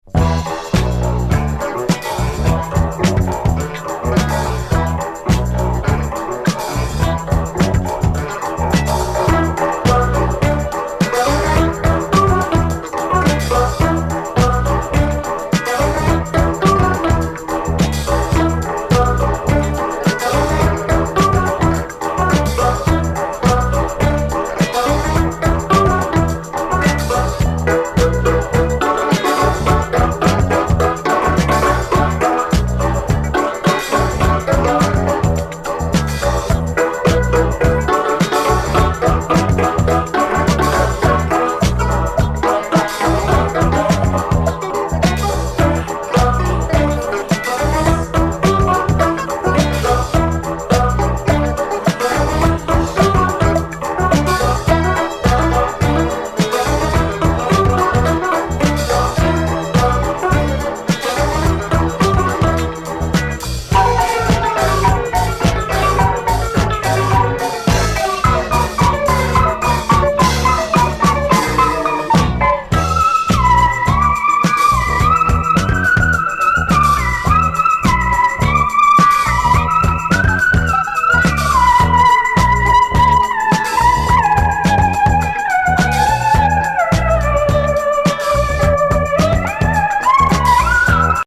solid dancefloor funky disco burners
Disco Funk